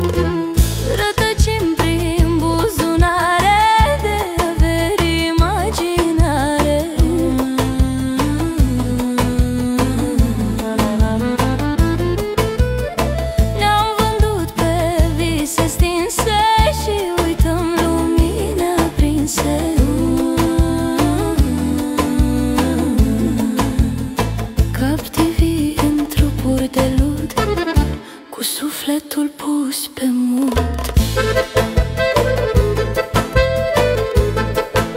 Скачать припев